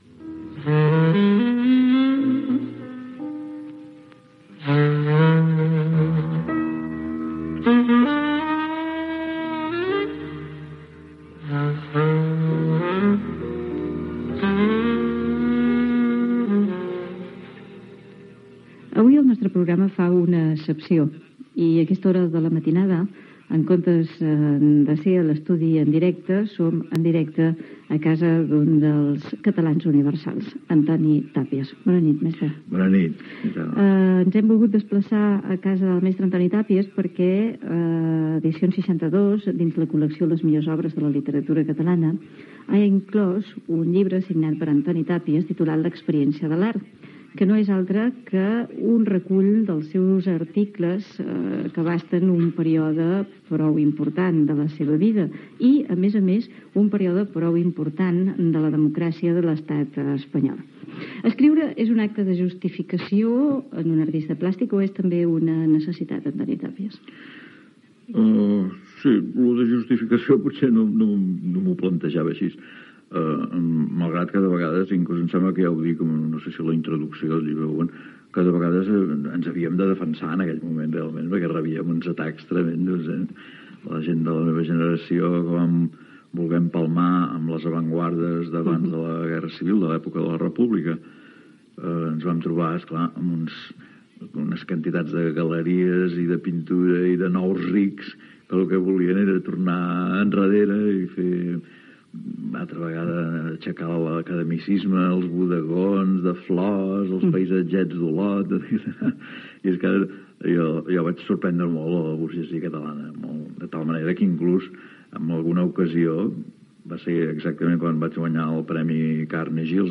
Careta del programa i entrevista al pintor Antoni Tàpies, feta a la seva casa de Barcelona. S'hi parla del llibre "L'experiència de l'art" que acaba de publicar